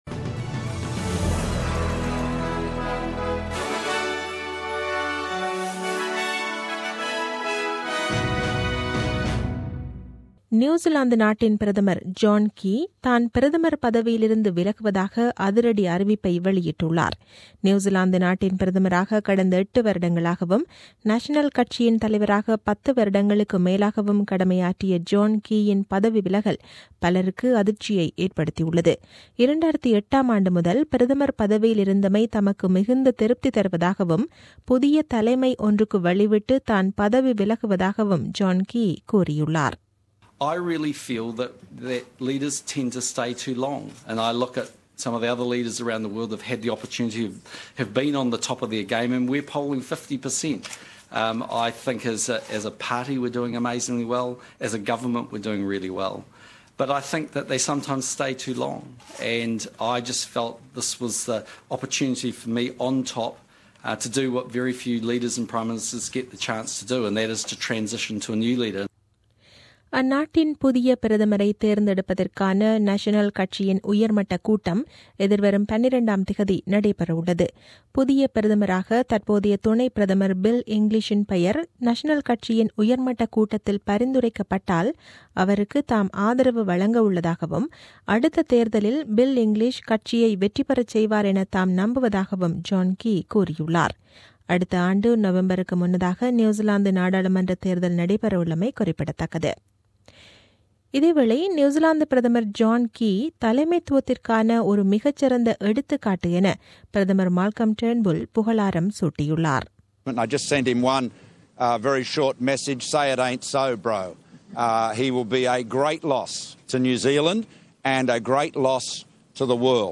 The news bulletin aired on 05 Dec 2016 at 8pm.